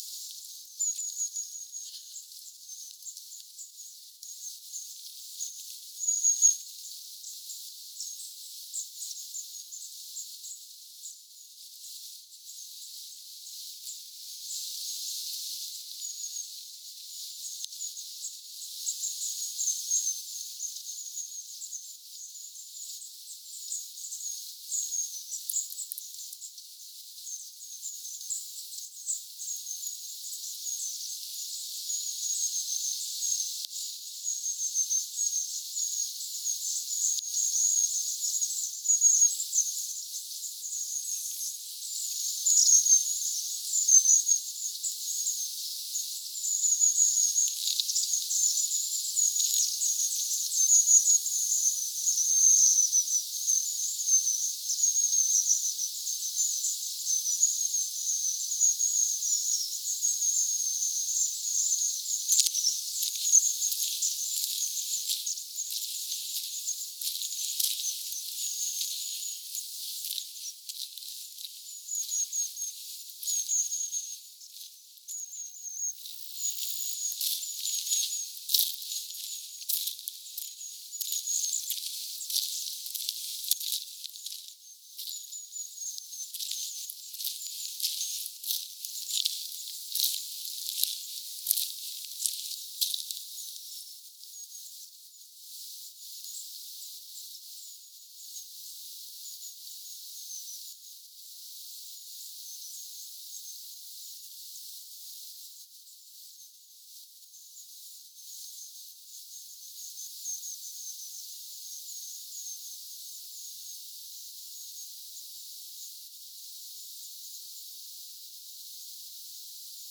sen 52 pyrstötiaisen parven ääntelyä
Ainakin ääntelyä on paljon.
tassa_taitaa_olla_sen_ison_52_pyrstotiaisparven_aantelya.mp3